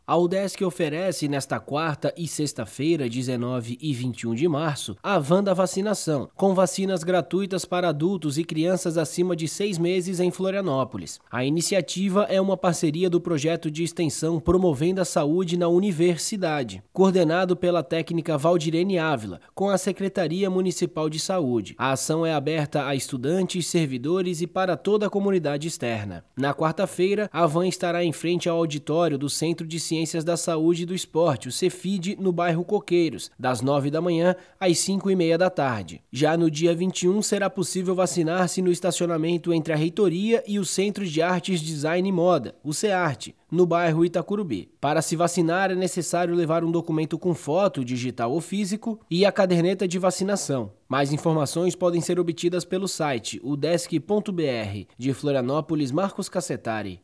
BOLETIM – Udesc disponibiliza vacinação gratuita em Florianópolis nesta quarta e sexta-feira